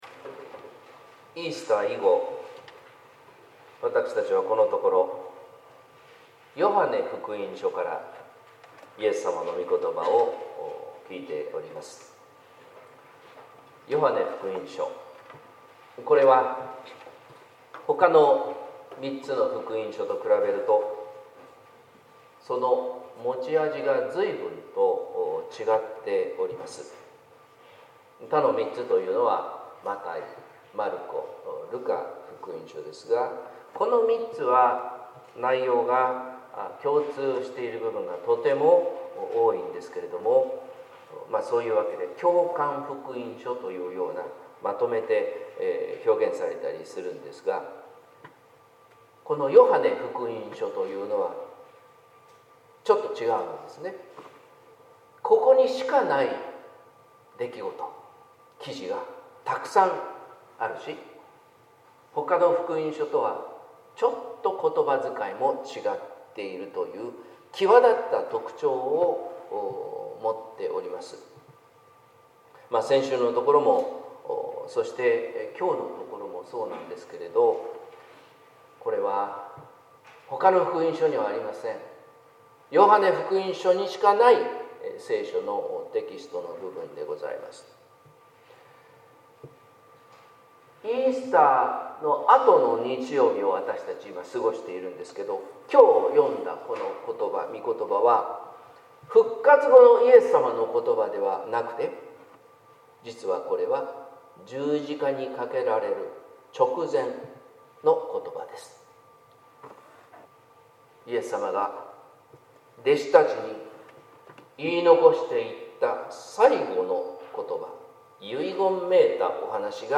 説教「イエスの喜びに満たされて」（音声版） | 日本福音ルーテル市ヶ谷教会